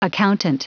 Prononciation du mot accountant en anglais (fichier audio)
Prononciation du mot : accountant